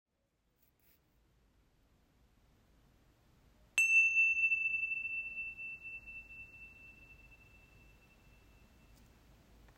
Tingshas gravés · 7,5 cm | Zen and Sounds - Instrument vibratoire de sonothérapie et musicothérapie idéal pour séances de yoga et méditation
Paire de Crotales Tingsha gravés.